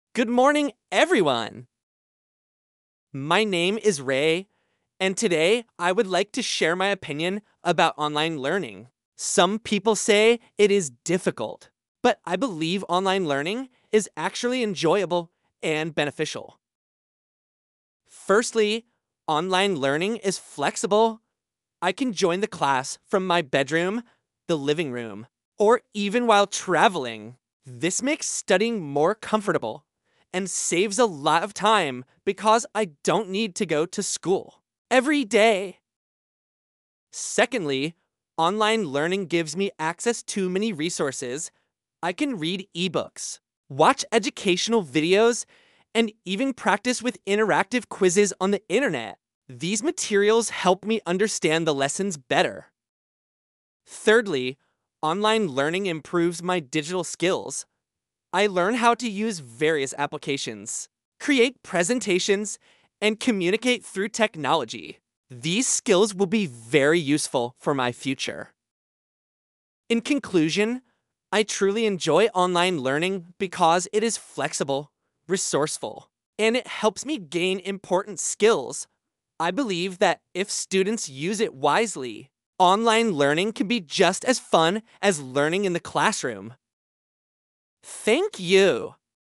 11-exposition-text-online-learning.mp3